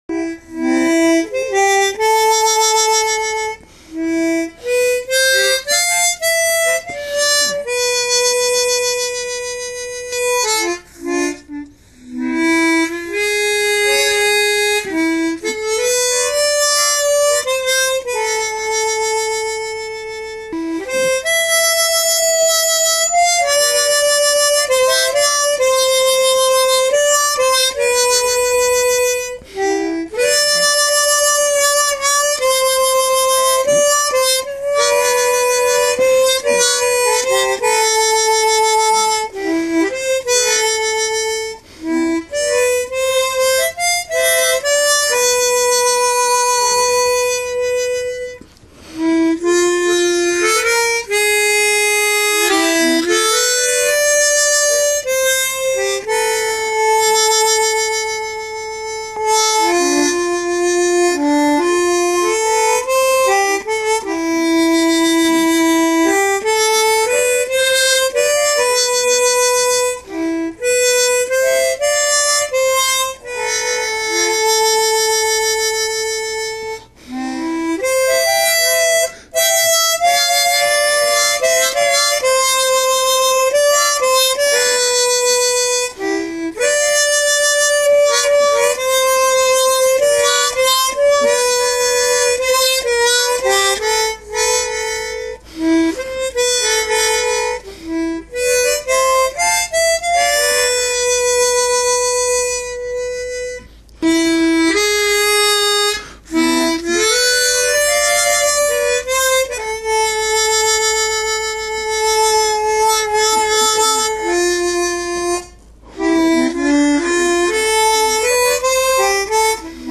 còn bên dưới là tùy hứng và là "nghiệp dư", cây nhà lá vườn